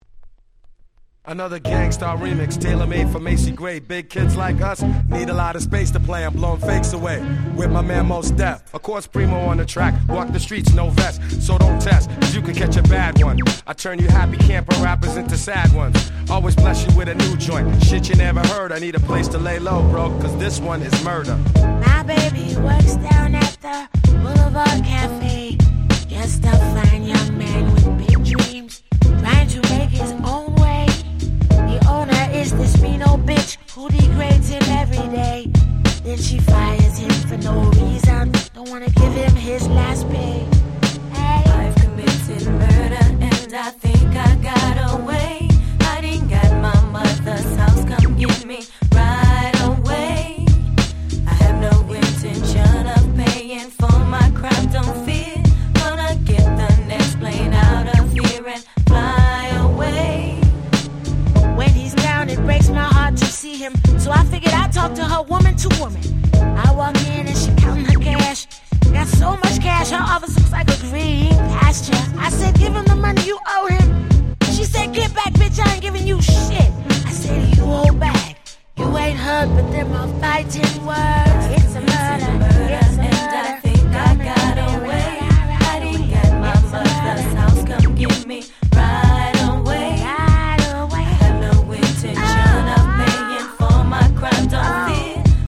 00' Nice Neo Soul !!
2枚使いがしっくり来ると言うか、地味なんだけどフロア映えすると言うか。
ネオソウル